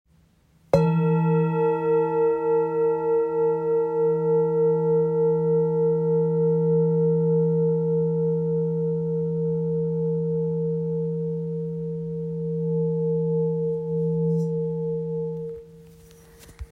Handcrafted by skilled artisans, each bowl is designed for both striking and singing, producing clear, harmonious sounds with a wide range of tones, long-lasting vibrations, and stable overtones.